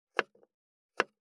512切る,包丁,厨房,台所,野菜切る,咀嚼音,ナイフ,調理音,まな板の上,料理,
効果音